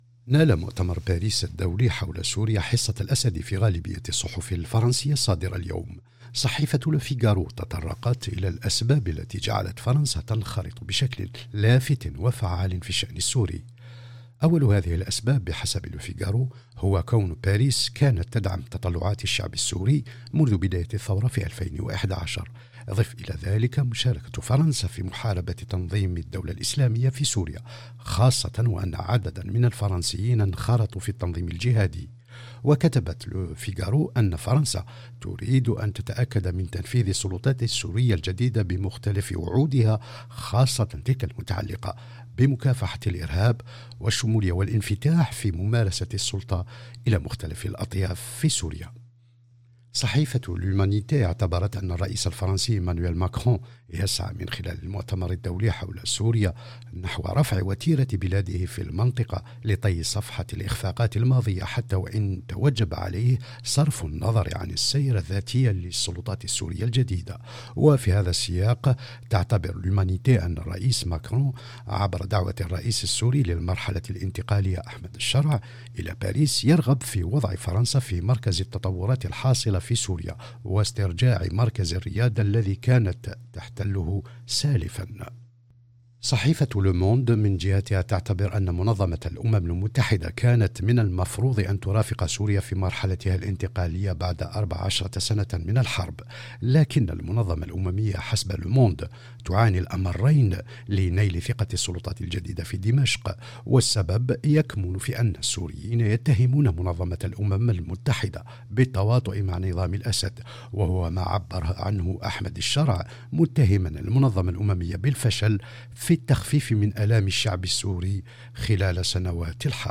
Revue de presse 13/02/2025